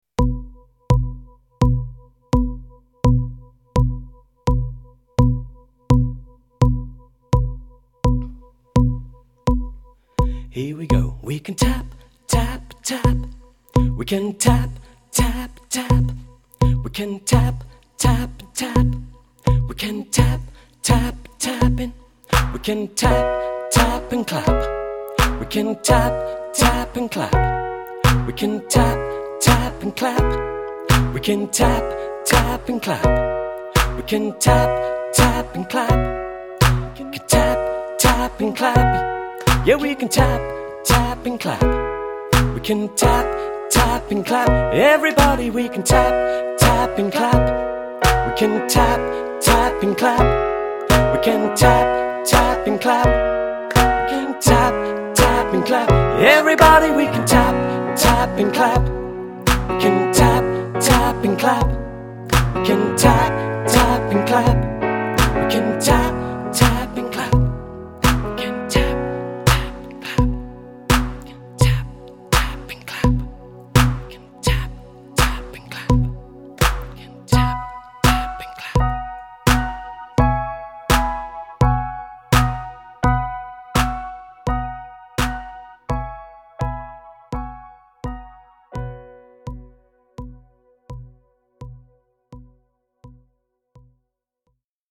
Simple+beat+(tap+and+clap)+building+block.mp3